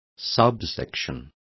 Complete with pronunciation of the translation of subsections.